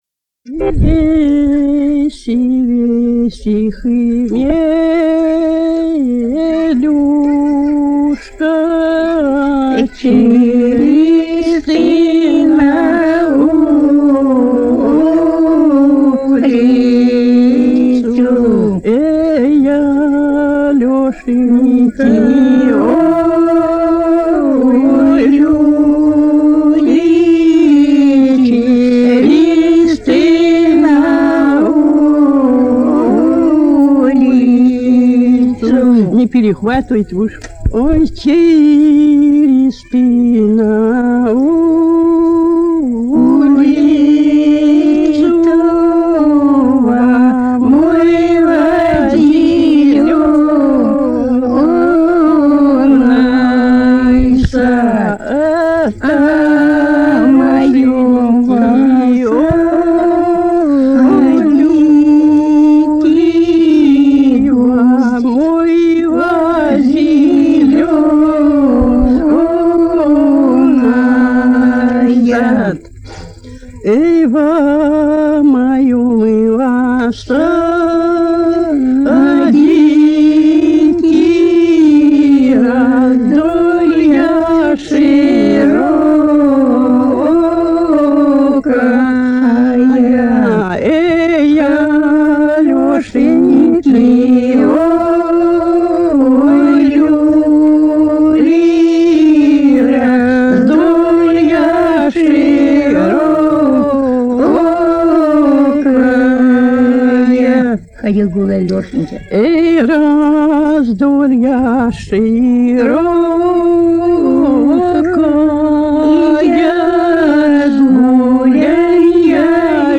Народные песни Касимовского района Рязанской области «Вейси, вейси, хмелюшка», свадебная.
02_-_02_Вейси,_вейси,_хмелюшка,_свадебная.mp3